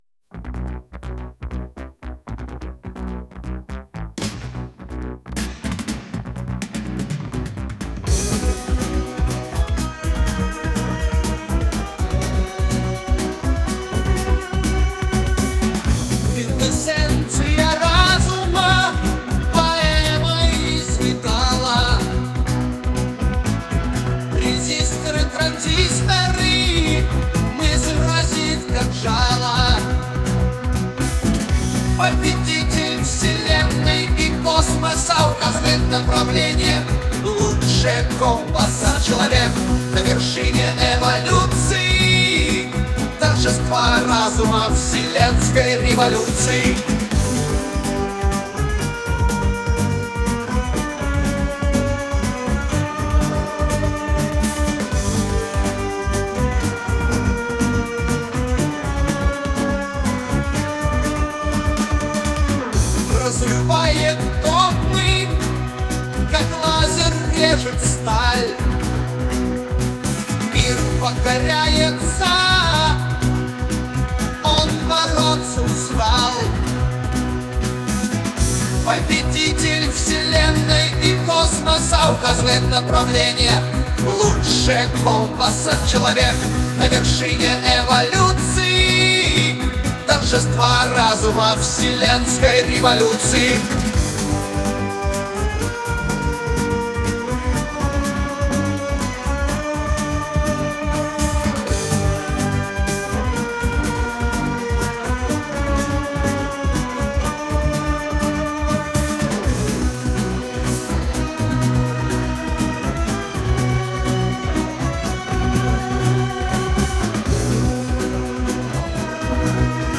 И вот затихание возмущения ИИ, но все еще с его проявлением:
Судя по всему в конце звучит ядерный взрыв.